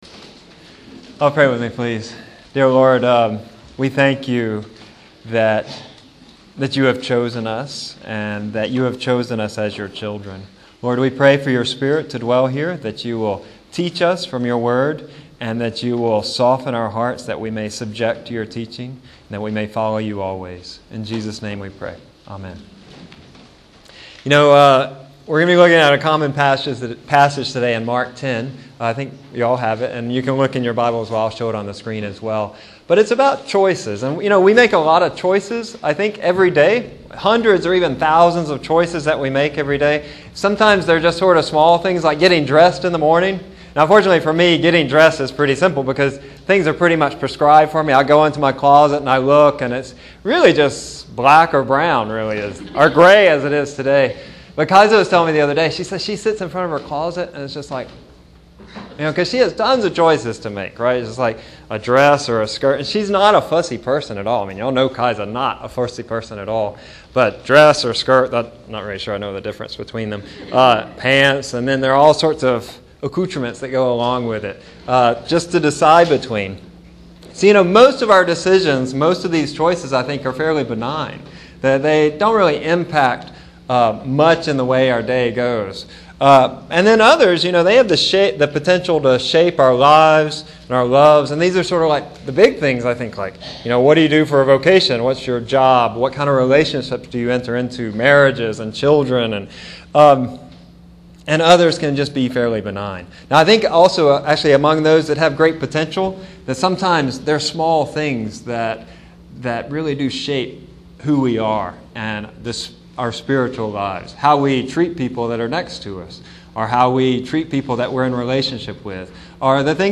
Visiting Preachers – The Bayou Christian